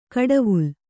♪ kaḍavul